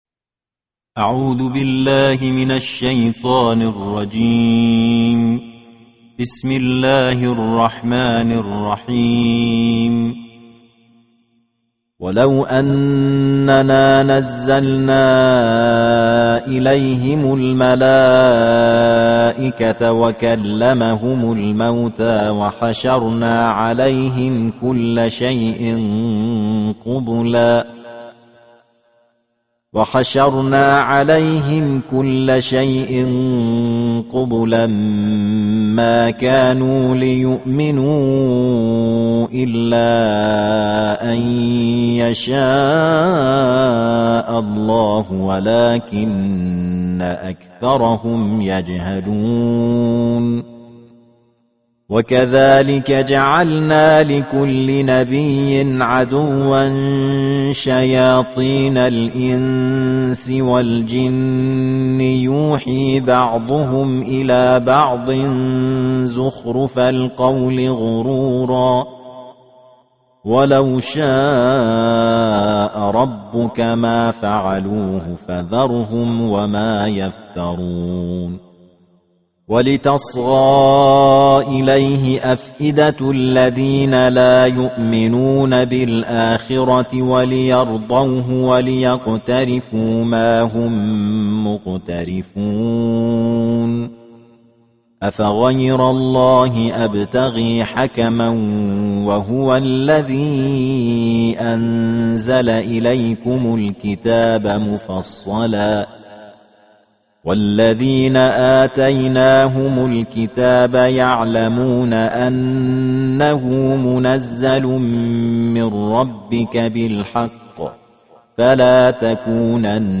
karatun kur'ani mai tsarki juzu'i na takwas